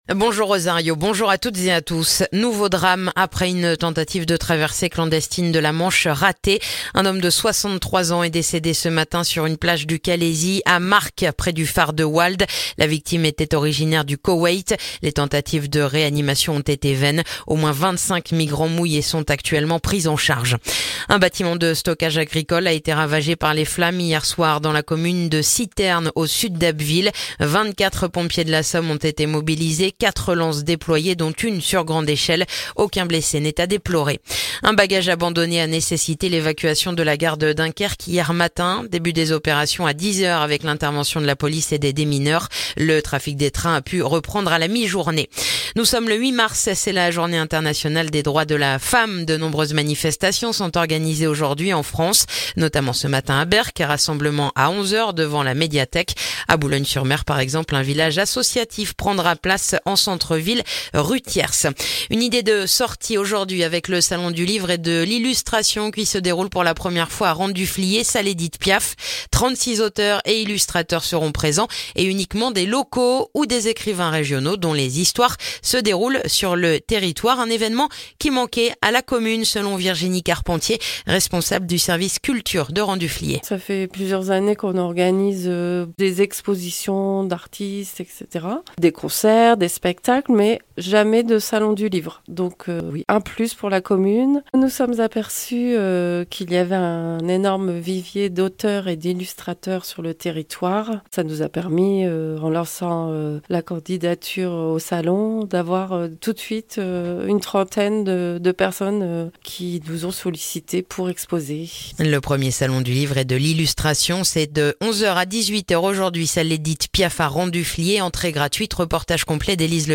Le journal du samedi 8 mars